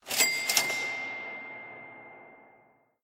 snd_buysomething.ogg